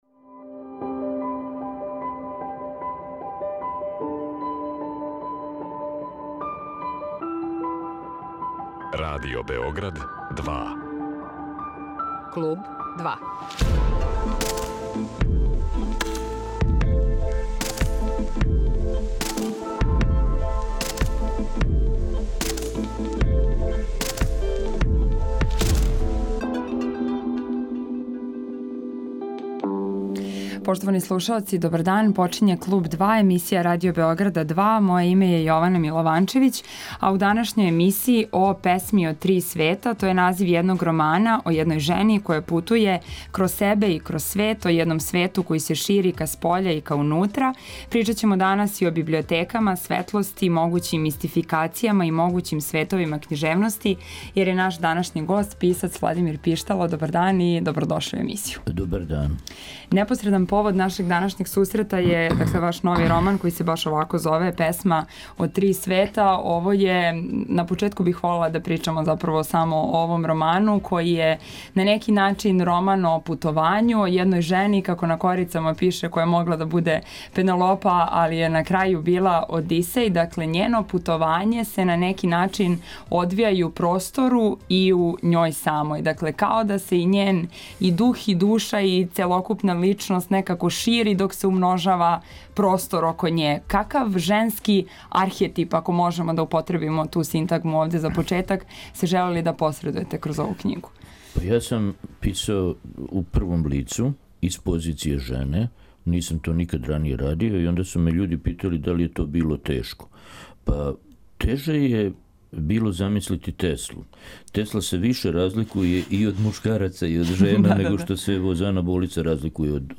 Гост Клуба 2 је писац Владимир Пиштало.